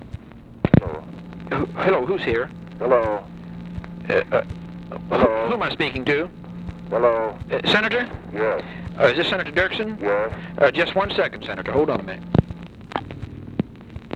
Conversation with JACK VALENTI and EVERETT DIRKSEN, April 9, 1964
Secret White House Tapes